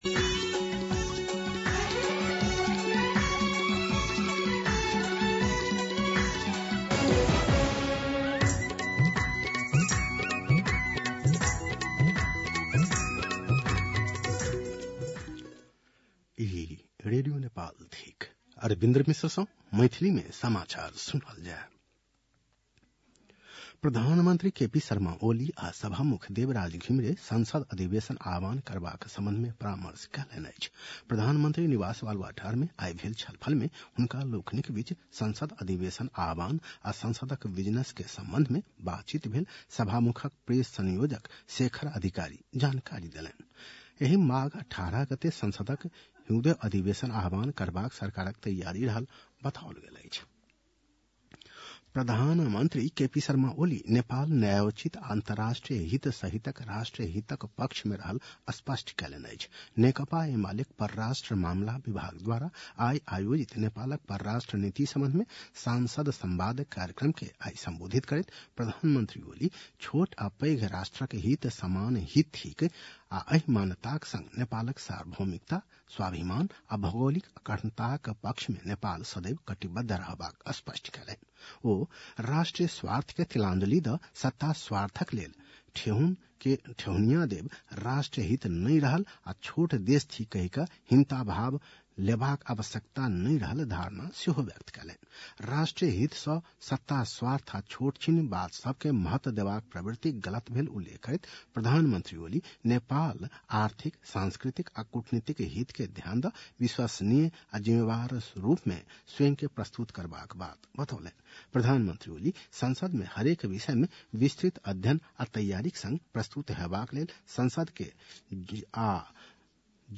मैथिली भाषामा समाचार : ८ माघ , २०८१